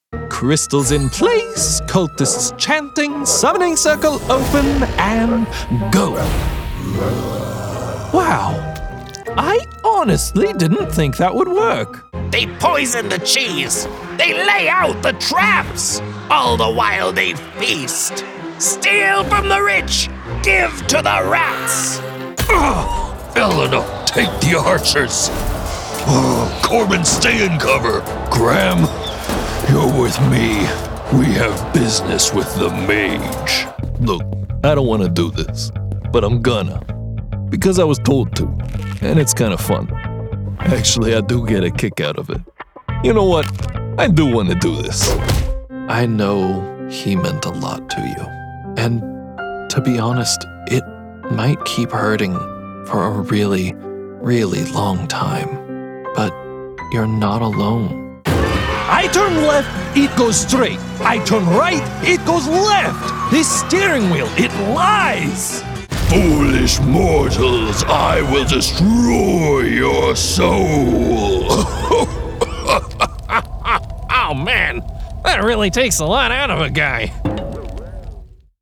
Teenager, Young Adult, Adult
Has Own Studio
ANIMATION 🎬